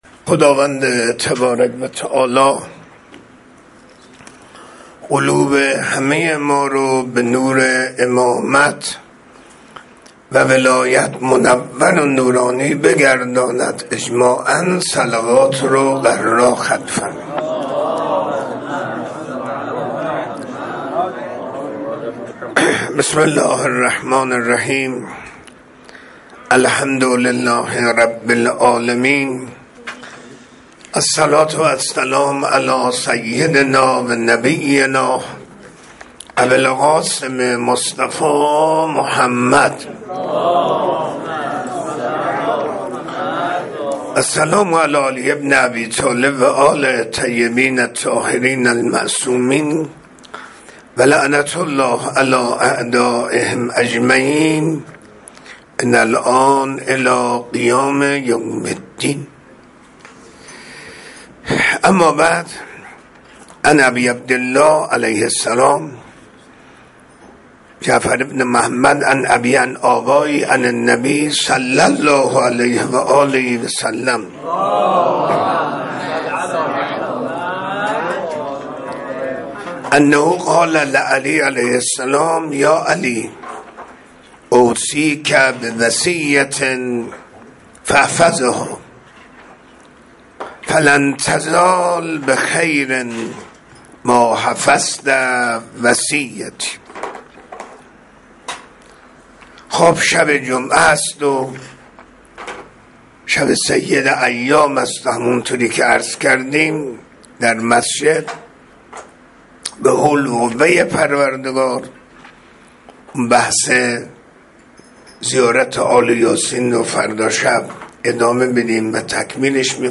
منبر 13 مرداد 1404